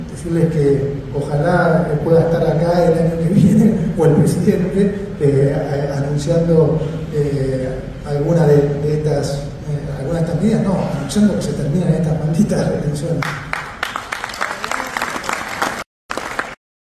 El ministro vendedor de buen futuro dijo: “Ojalá que pueda estar acá el año que viene, o el Presidente, anunciando que se terminan las malditas retenciones”, y se escucharon aplausos.